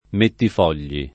mettifoglio [ mettif 0 l’l’o ]